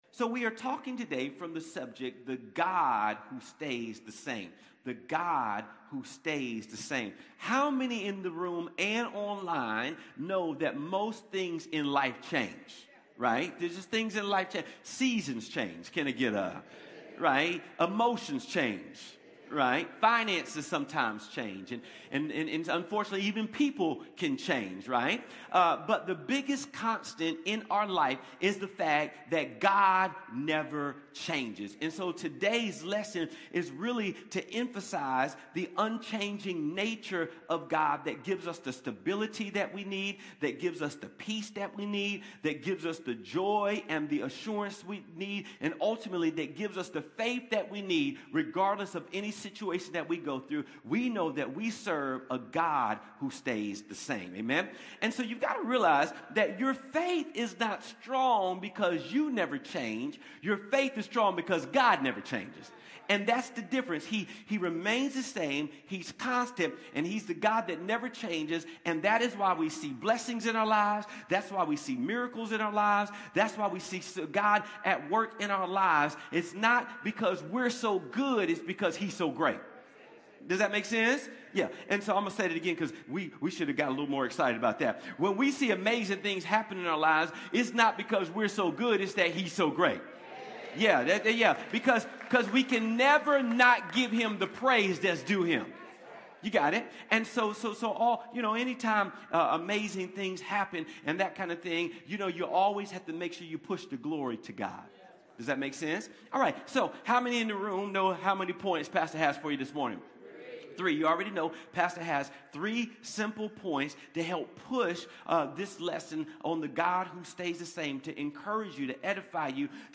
Connect Groups Events Watch Church Online Sermons Give The God Who Stays the Same November 16, 2025